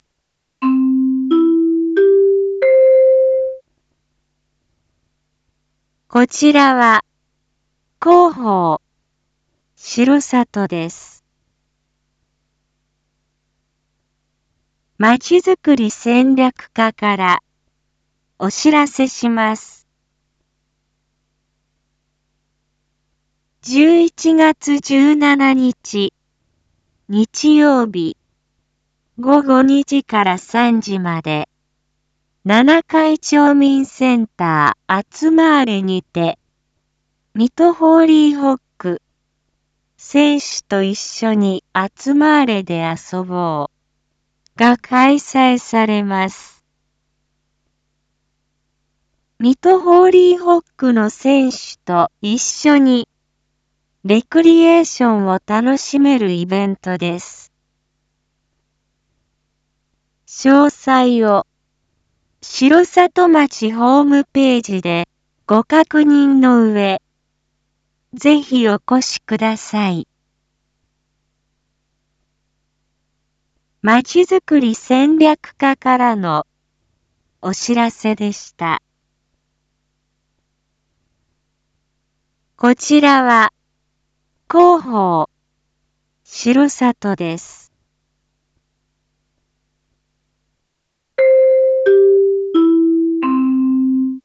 Back Home 一般放送情報 音声放送 再生 一般放送情報 登録日時：2024-11-15 19:01:36 タイトル：ＭＨＨ「選手と一緒にアツマーレで遊ぼう」 インフォメーション：こちらは、広報しろさとです。